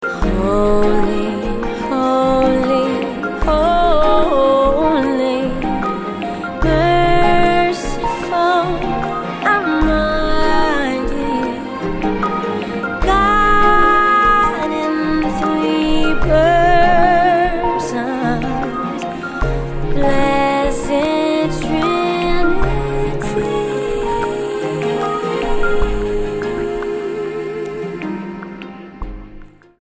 STYLE: Pop
smooth Anastasia-like vocals